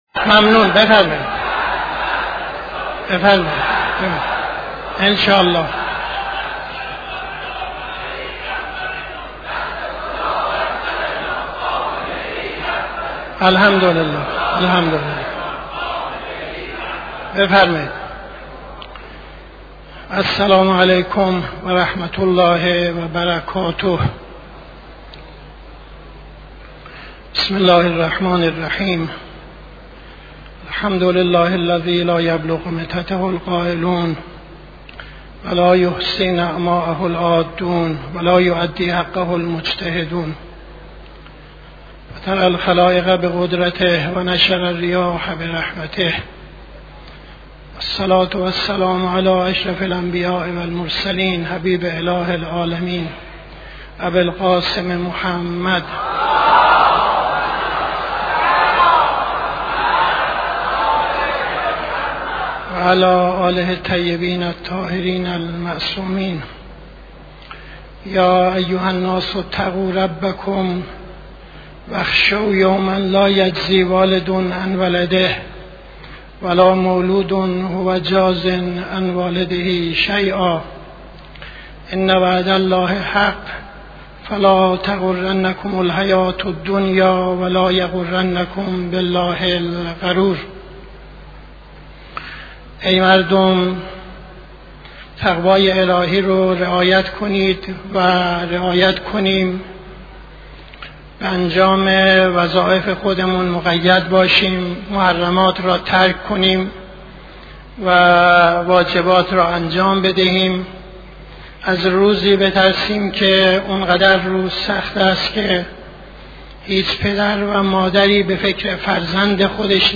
خطبه اول نماز جمعه 06-08-73